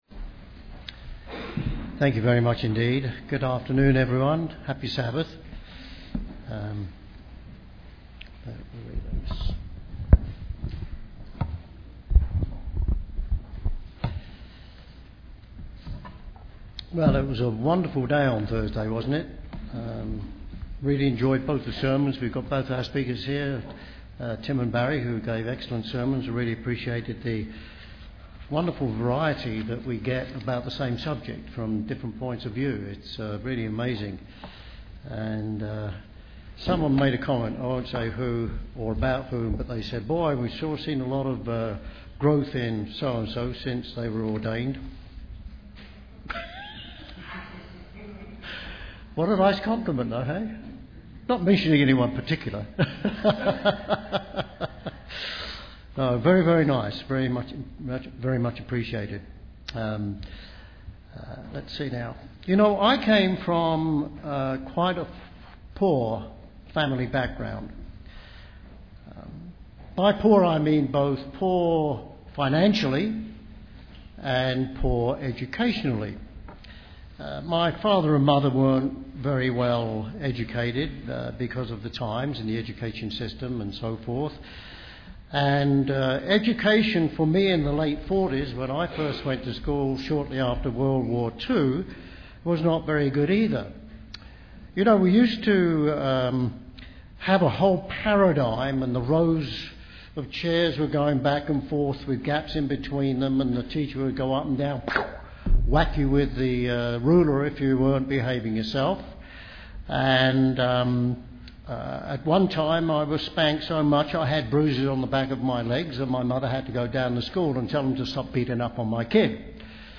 The purpose of this sermon is to demonstrate the simplicity of the core principles of God.